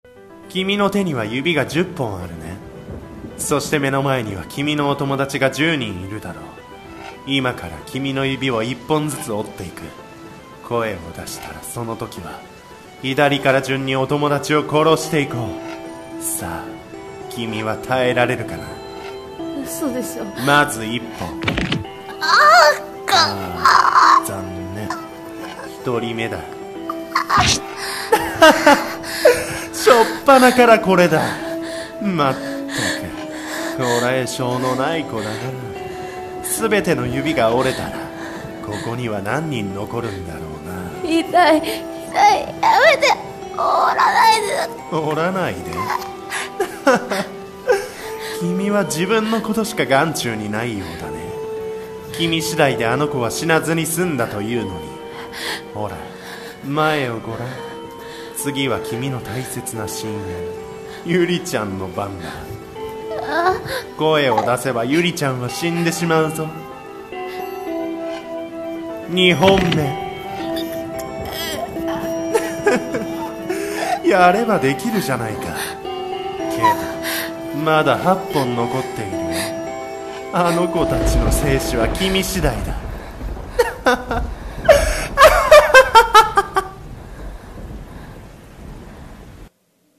【R15】指折り殺人鬼【ホラー声劇】